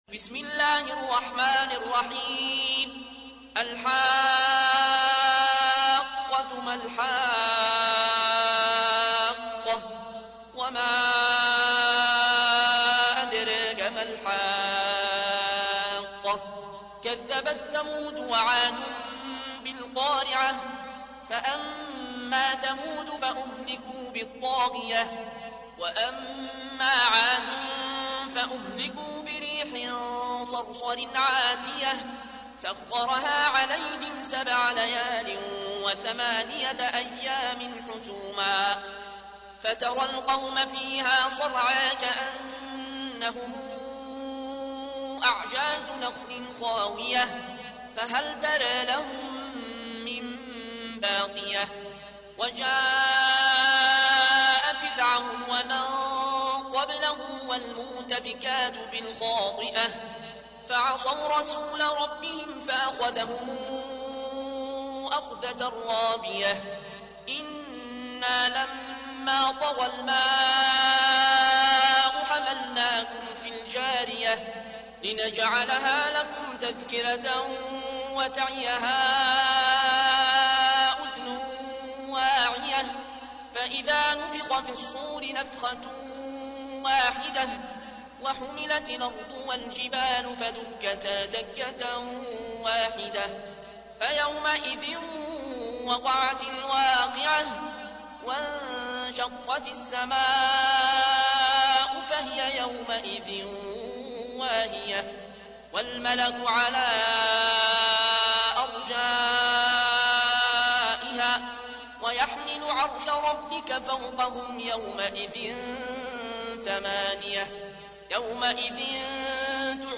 سورة الحاقة / القارئ